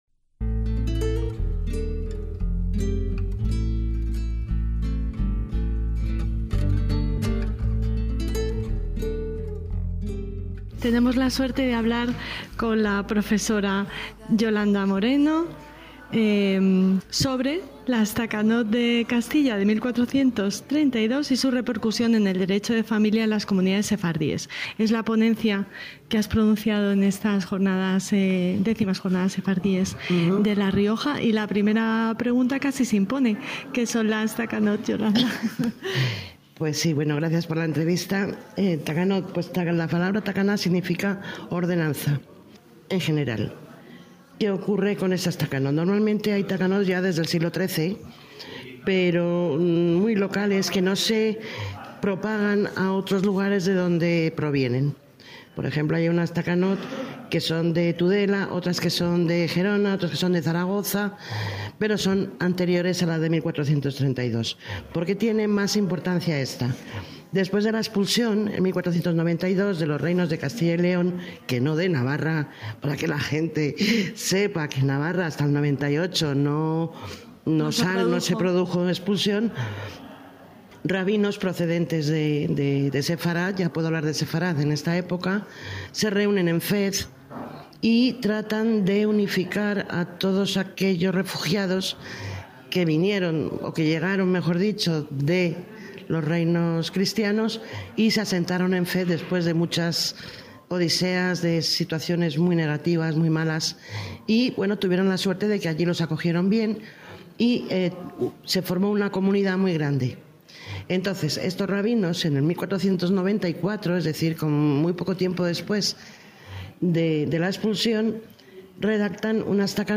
DESDE LAS X JORNADAS SEFARDÍES EN LA RIOJA –